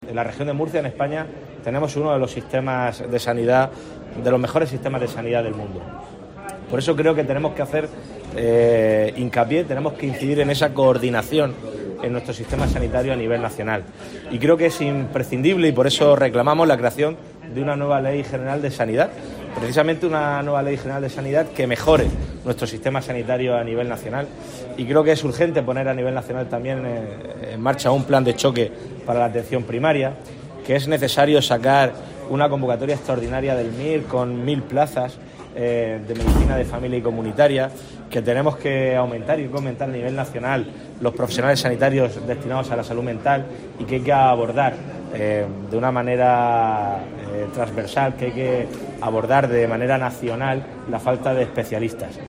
Durante la inauguración de la jornada ‘Retos actuales para una sanidad futura’, en el marco de la cumbre sanitaria europea que se celebra en Murcia conjuntamente con la Asamblea General de la Asociación Europea de Médicos Jóvenes (EJD), López Miras destacó que “la Región de Murcia se convierte desde hoy en la sede y centro de referencia para debatir las principales políticas para seguir reforzando el sistema sanitario en toda Europa”.